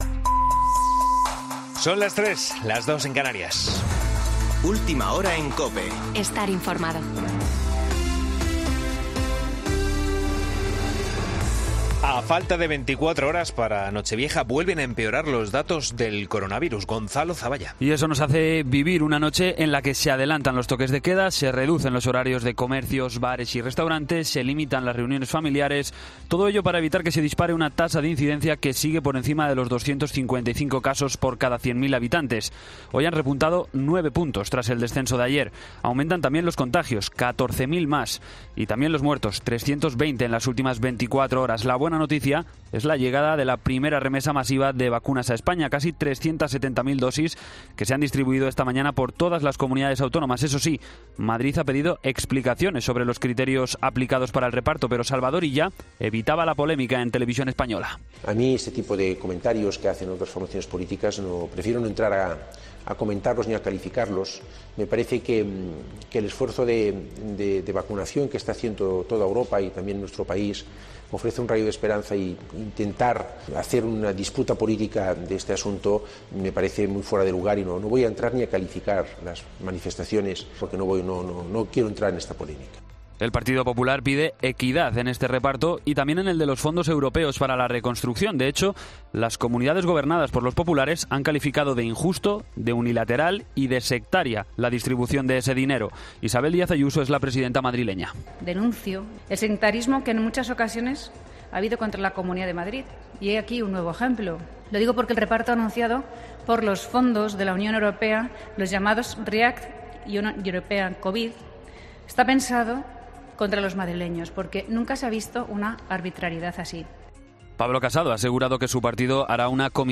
Boletín de noticias COPE del 30 de diciembre de 2020 a las 03.00 horas